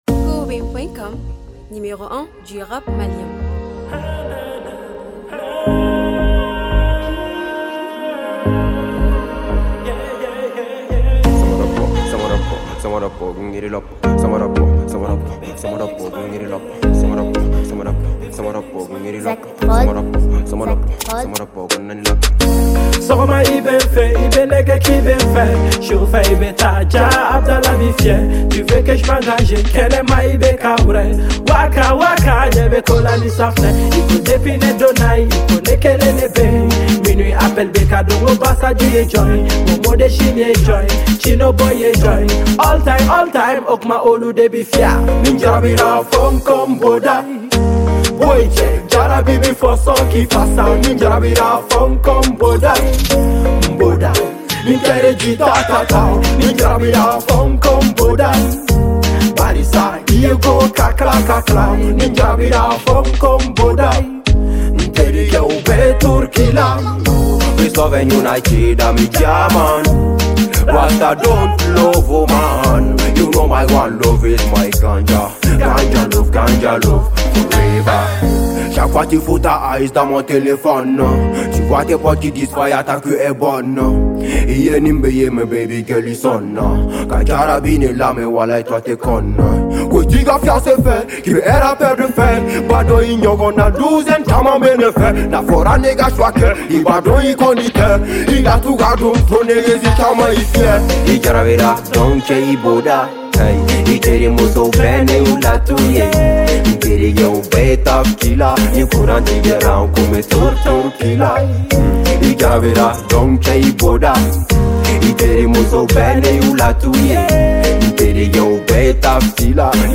musique Mali.